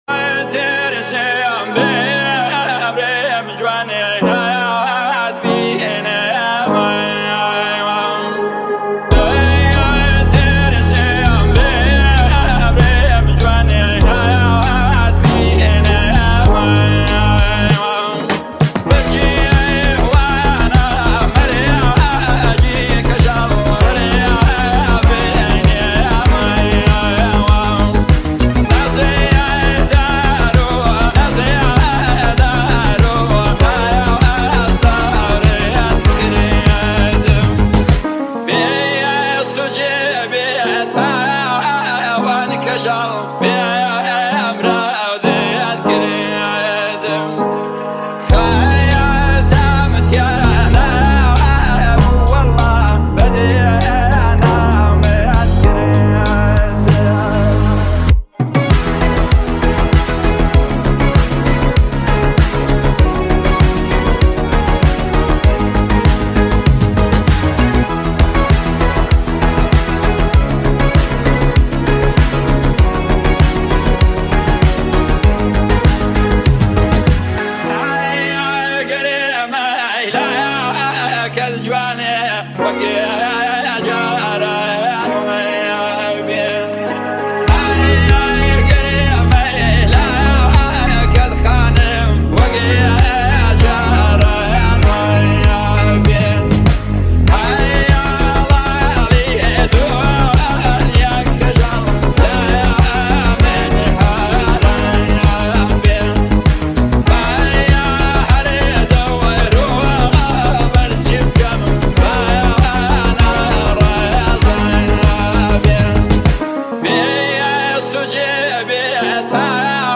ترانه جاودانه و نوستالژیک کردی